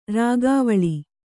♪ rāgāvaḷi